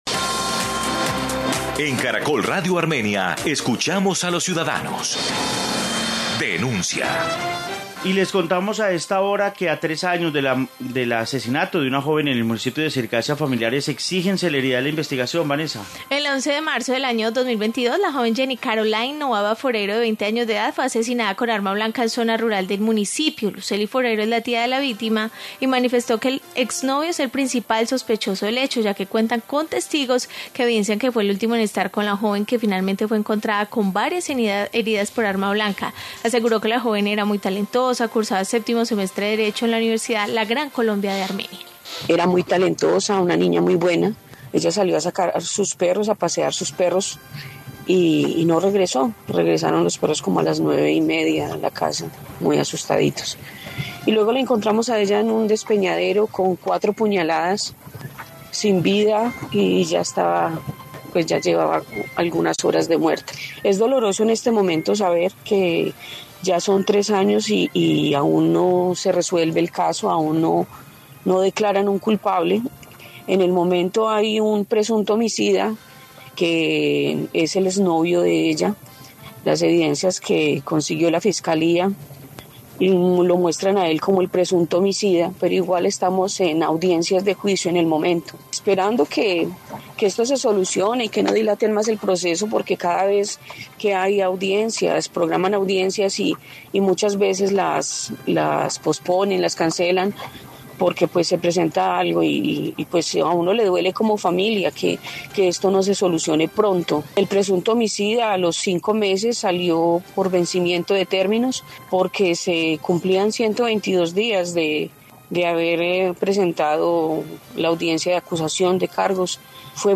Informe caso joven Circasia